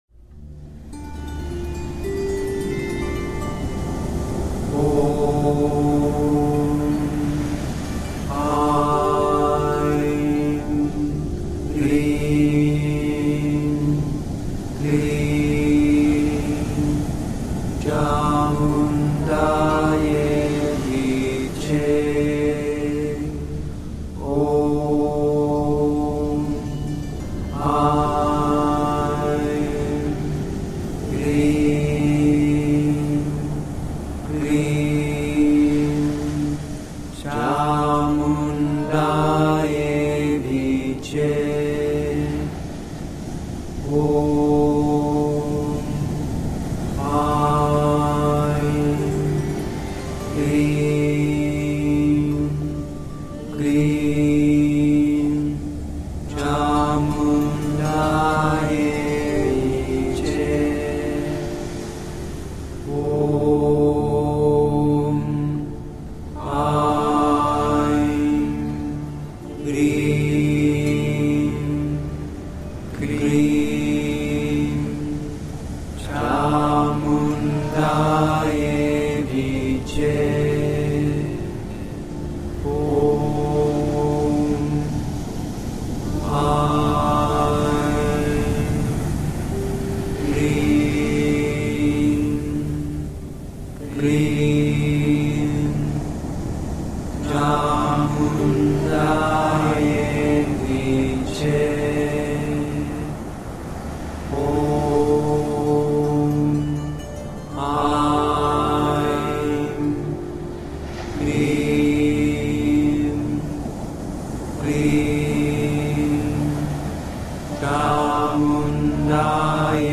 Genre: New Age.